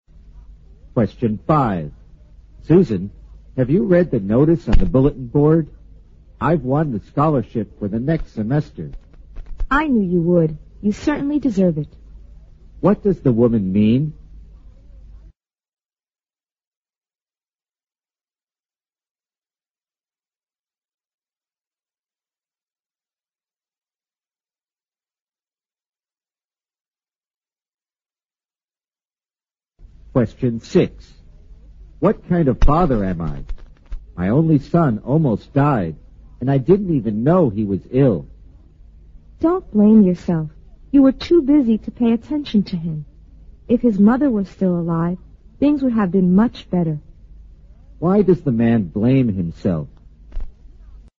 新编六级听力短对话每日2题 第160期